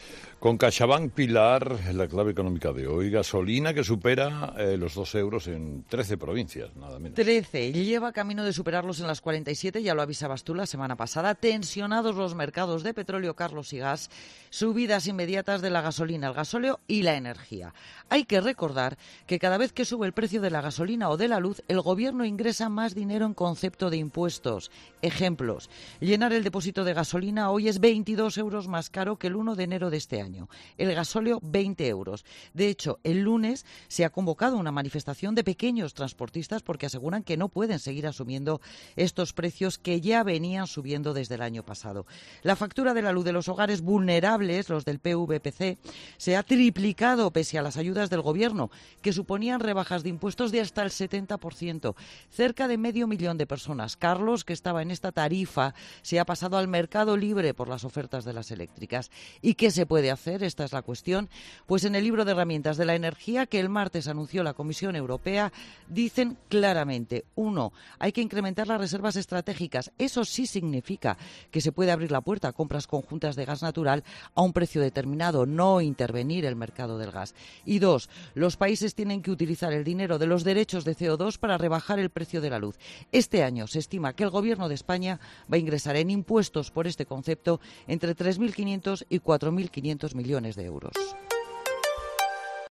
En Herrera en COPE, la periodista, especializada en economía y finanzas, Pilar García de la Granja ha recordado que "cada vez que sube el precio de la gasolina o de la luz, el Gobierno ingresa más dinero en concepto de impuestos".